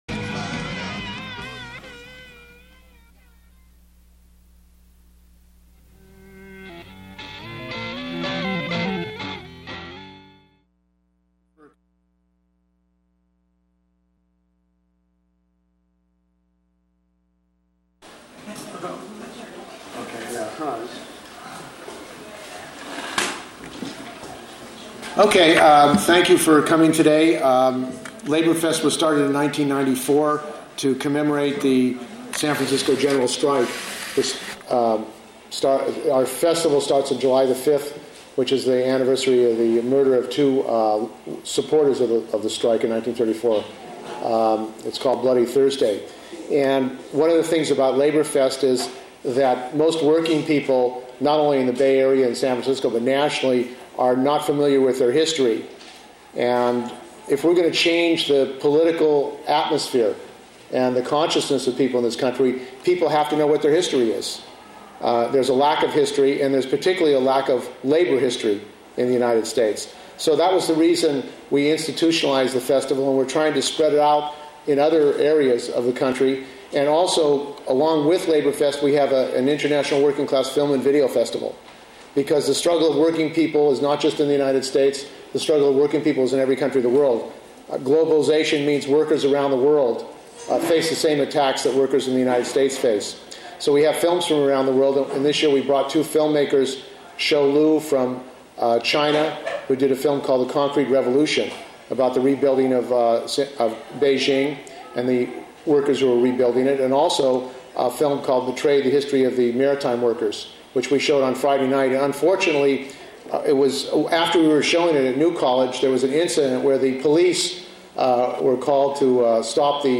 LaborFest lecture on the public works projects built by workers during the great depression